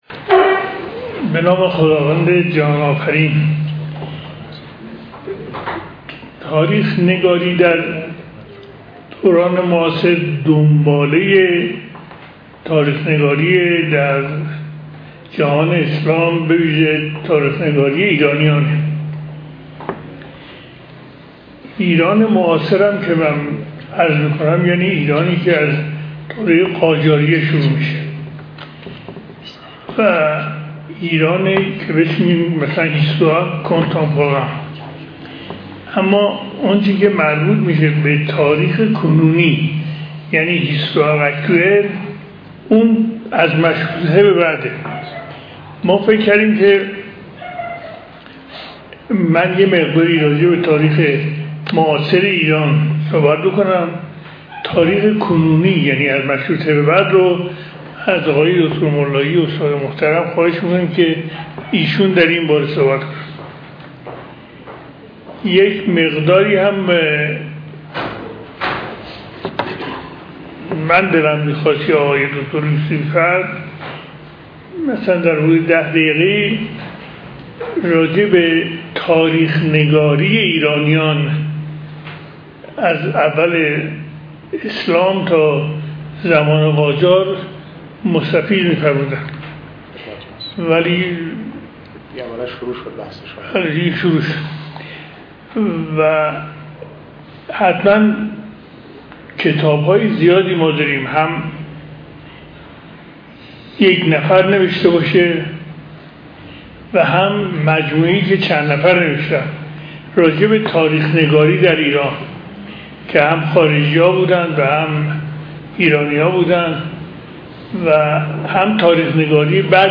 سخنرانی
در خانه اندیشمندان علوم انسانی